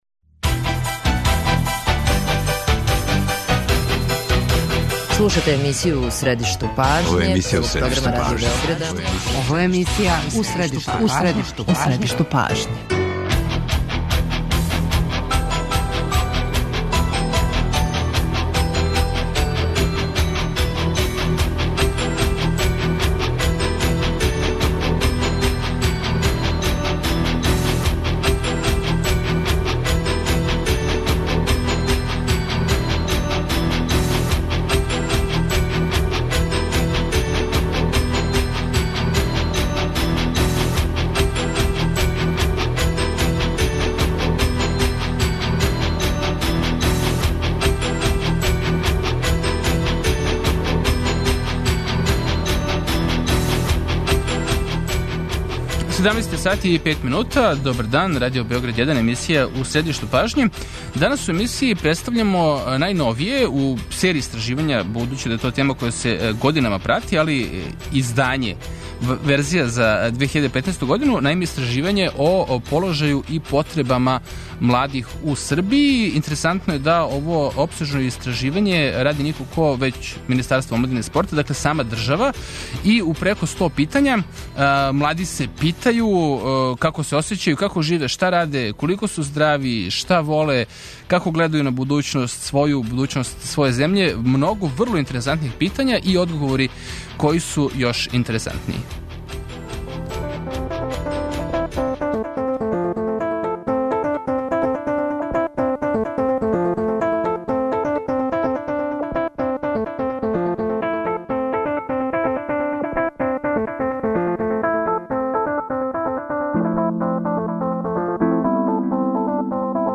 Опсежно истраживање о потребама и положају младих које је спровело Министарство омладине и спорта показало је да су стари проблеми још увек ту, али пружило је и много нових информација. О резултатима истраживања разгворамо са представницима министарства и омладинских организација.